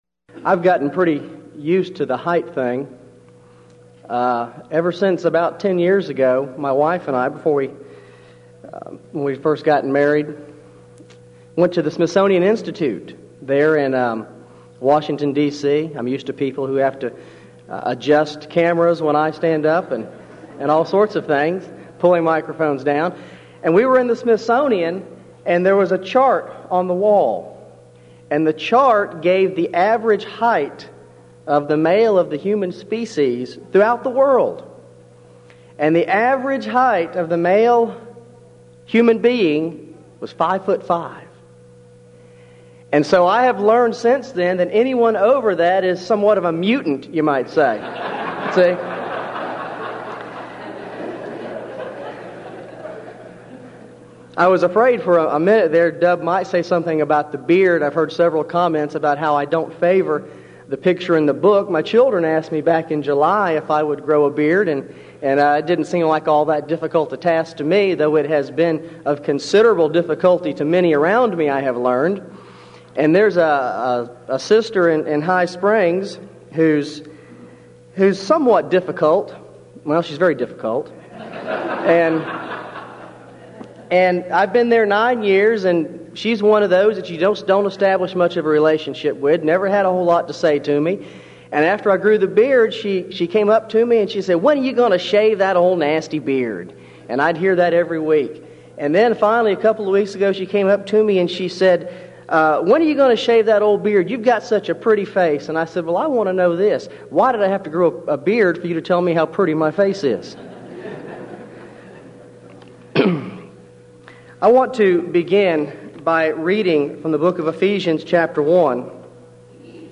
Series: Denton Lectures Event: 16th Annual Denton Lectures Theme/Title: Studies In Ephesians